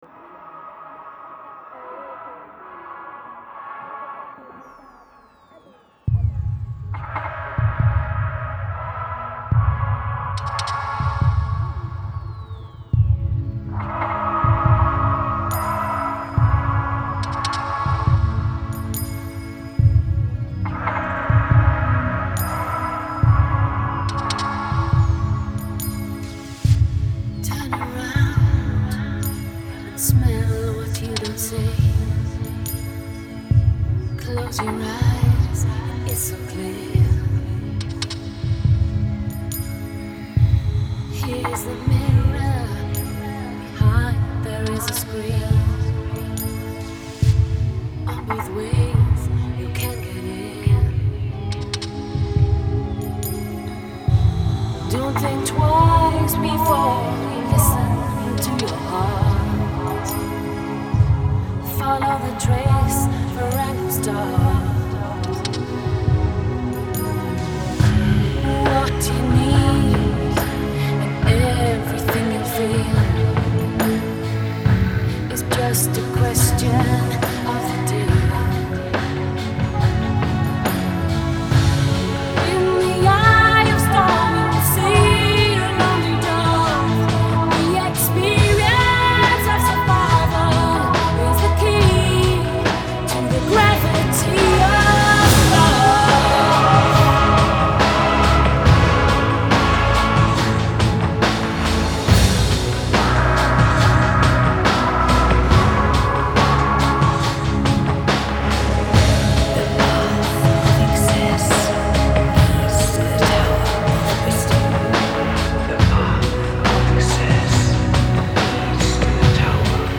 Жанр: New Age Альбом